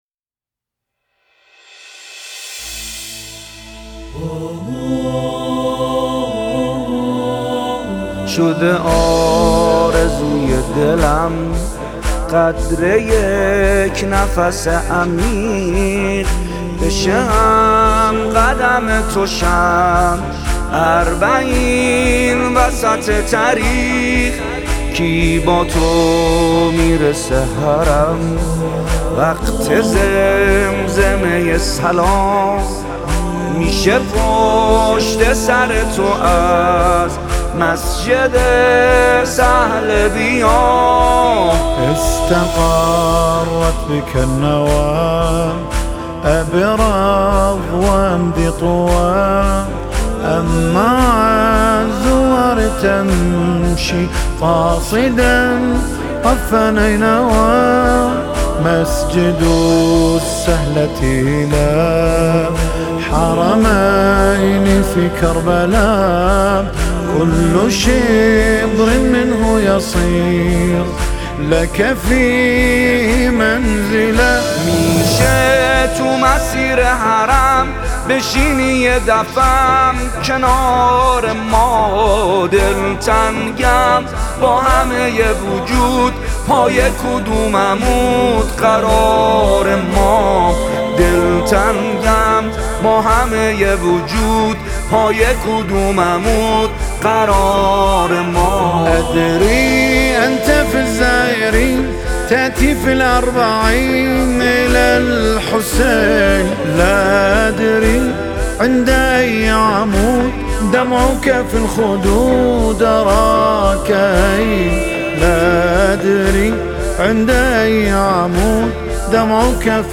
نماهنگ فارسی عربی